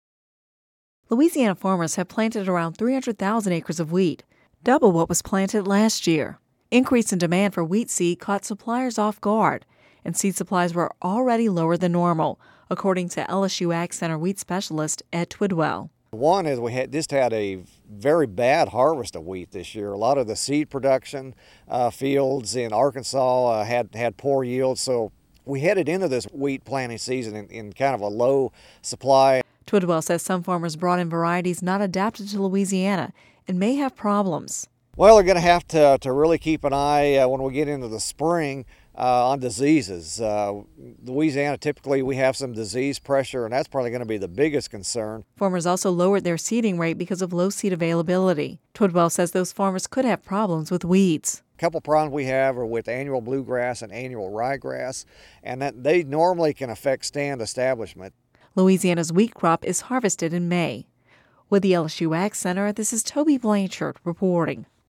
(Radio News 12/06/10) Louisiana farmers have planted about 300,000 acres of wheat – double what was planted last year.